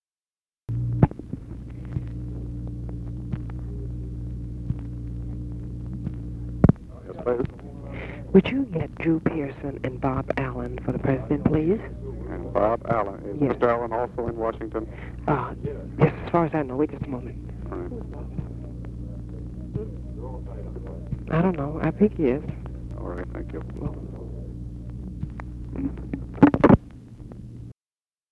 Secret White House Tapes